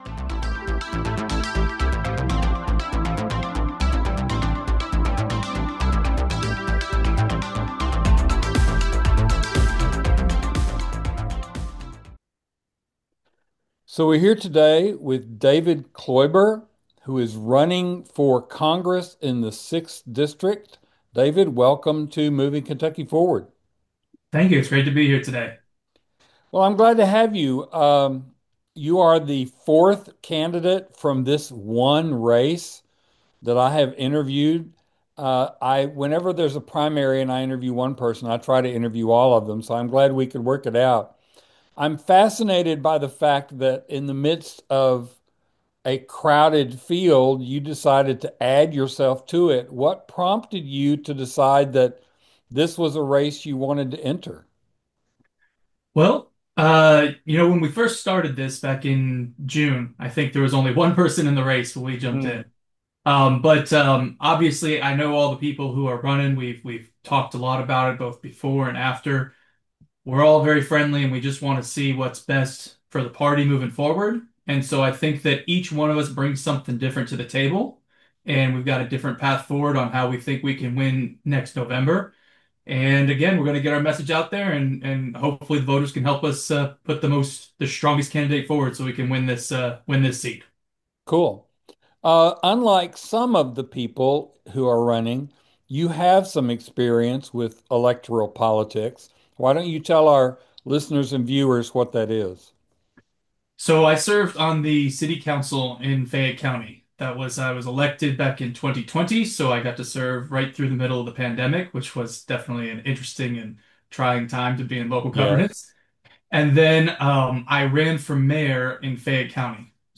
An interview w/ David Kloiber, candidate for KY-06